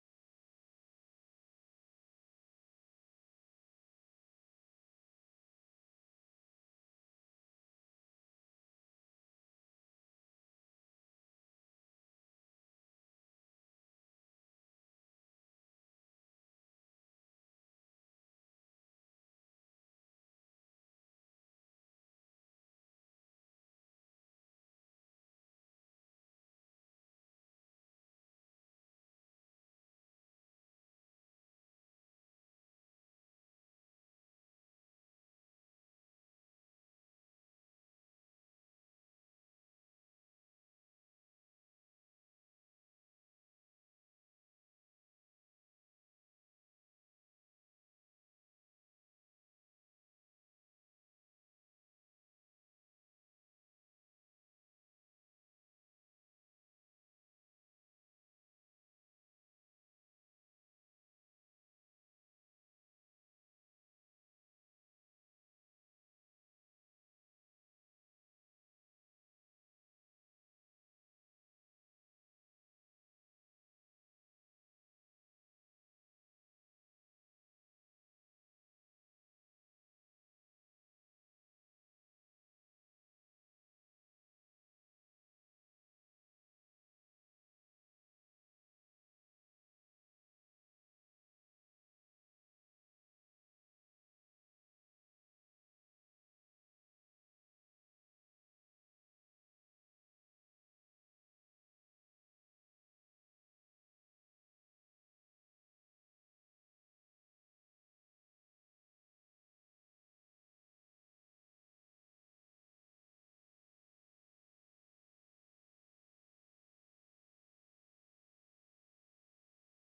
Worship from the 24th of September
Praise Worship